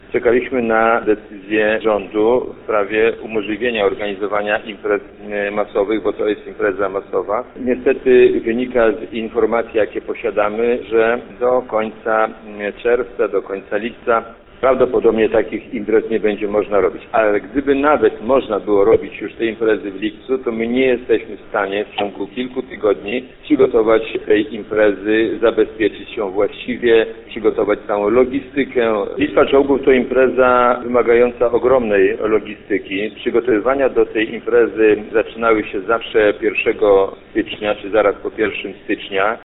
– To duże wyzwanie logistyczne – ocenia burmistrz Orzysza Zbigniew Włodkowski.